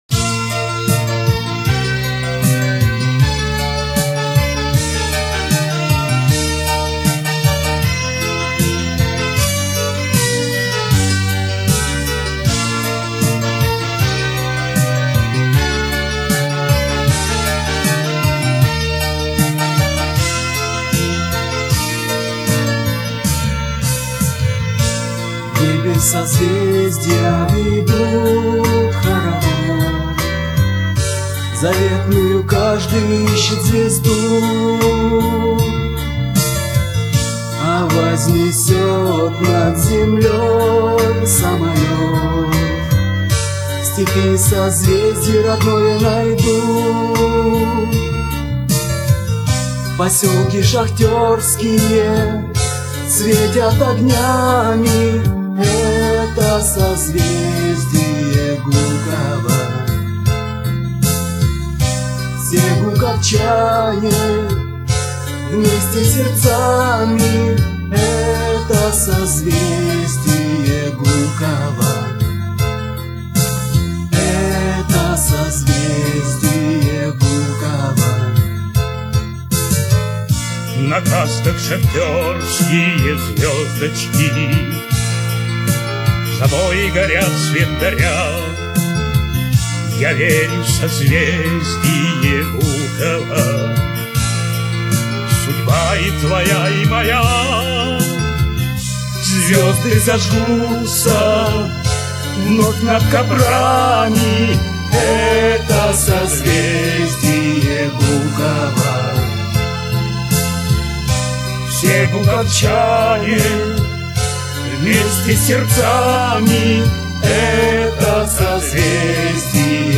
Исполняют песню – работники ДК «Антрацит» (1998 год).